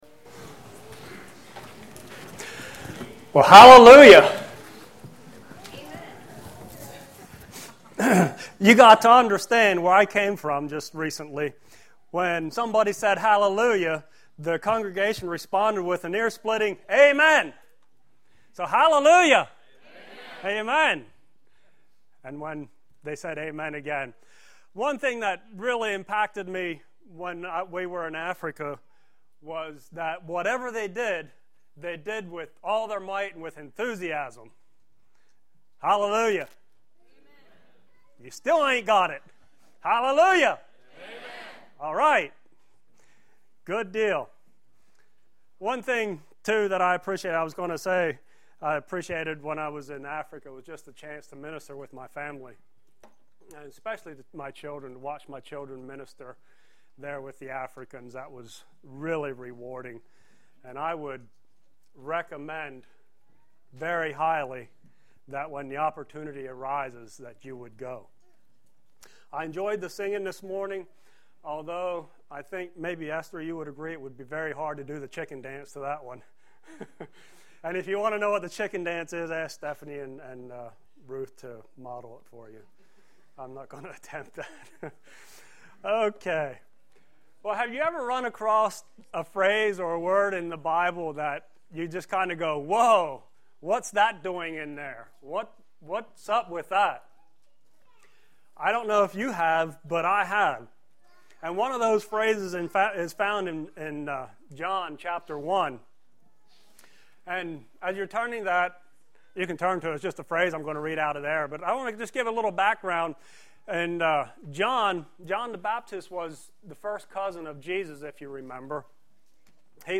Sermons – Page 63 – Shiloh Mennonite